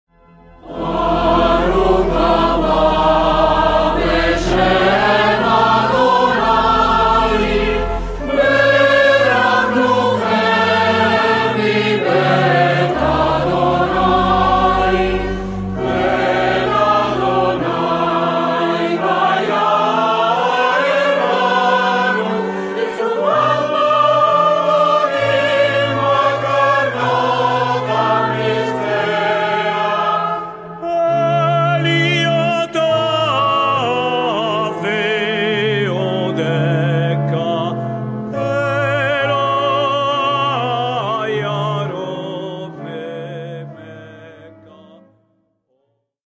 Coro e solista